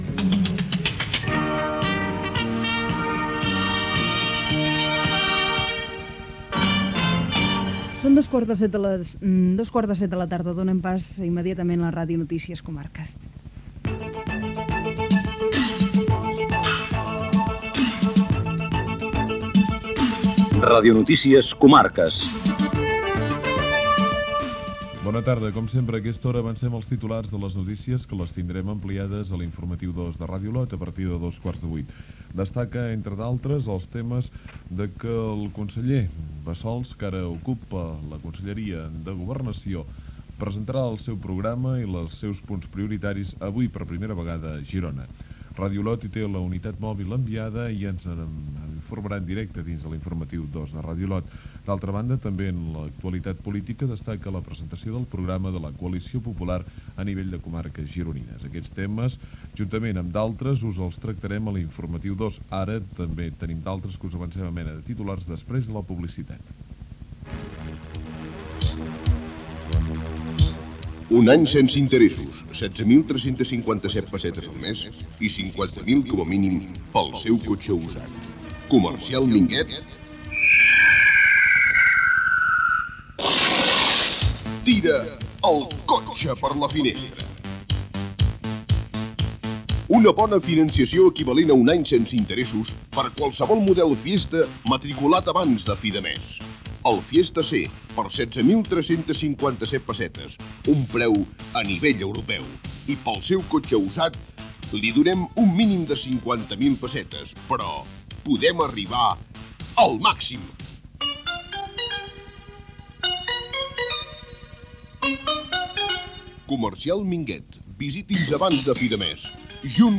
Hora, careta del programa, avanç de l'Informatiu 2: conseller Bassols presenta el seu programa a Girona.
Informatiu
FM